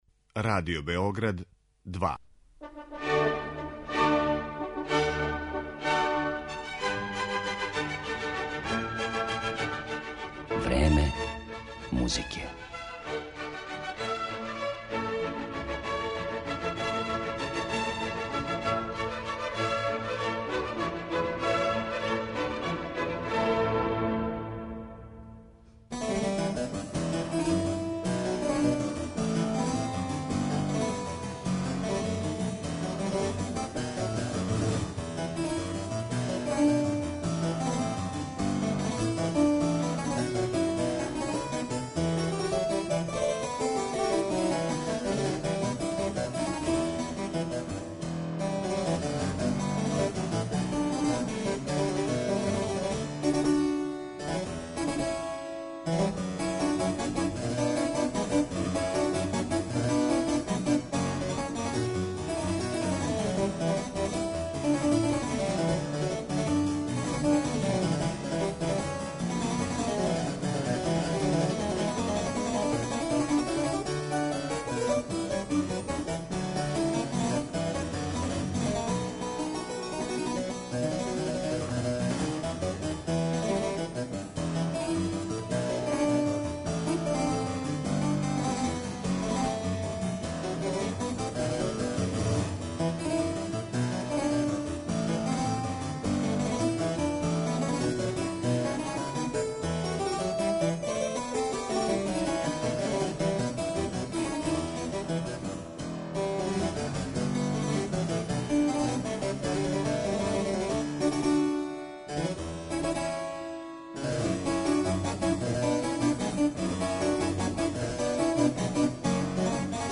Гост